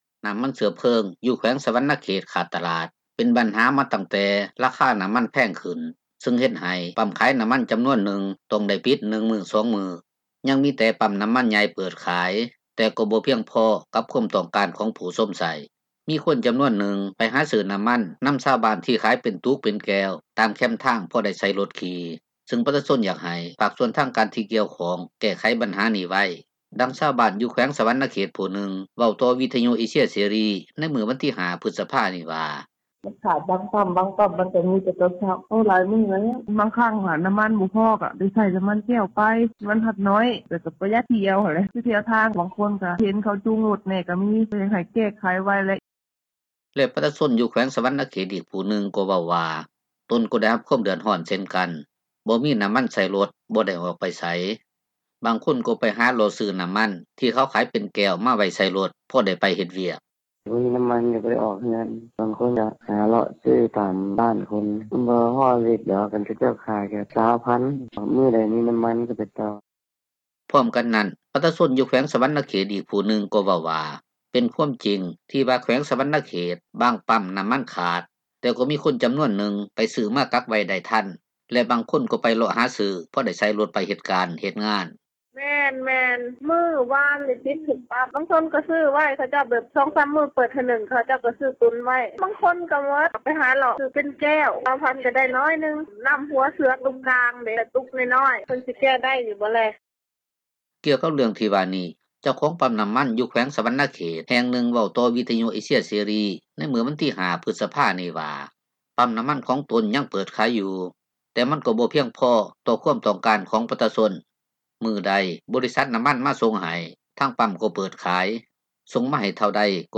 ດັ່ງຊາວບ້ານ ຢູ່ແຂວງສວັນນະເຊດຜູ້ນຶ່ງ ເວົ້າຕໍ່ວິທຍຸເອເຊັຽເສຣີໃນມື້ວັນທີ 5 ພຶສພານີ້ວ່າ: